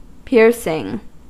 Ääntäminen
IPA : /ˈpɪɹ.sɪŋ/